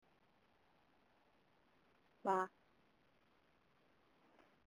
電話でブログ投稿〜BLOGROWN: